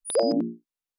pgs/Assets/Audio/Sci-Fi Sounds/Interface/Error 16.wav at 7452e70b8c5ad2f7daae623e1a952eb18c9caab4
Error 16.wav